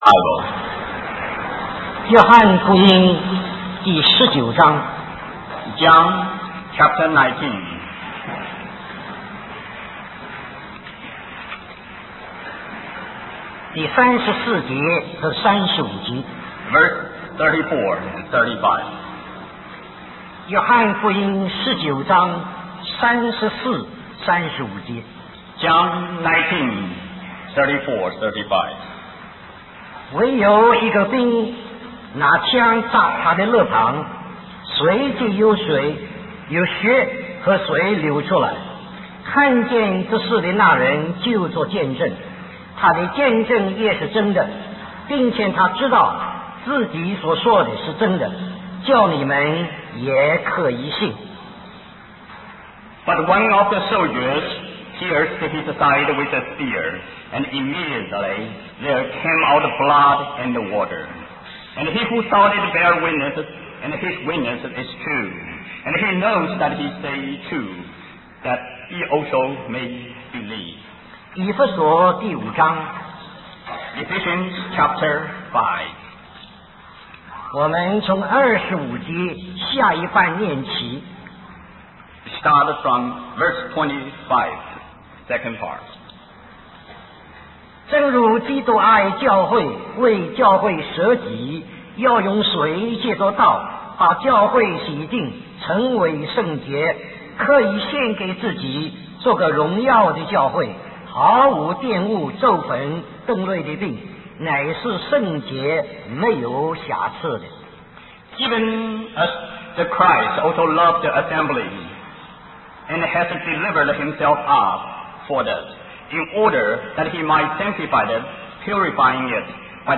In this sermon, the preacher discusses the significance of the water and blood that came out of Jesus' broken heart on the cross. The water represents Jesus' life, as it is mentioned in Psalm 22 that his life poured out like water. The blood represents the renunciation of sin, as Jesus' precious blood was shed to save us from our sins.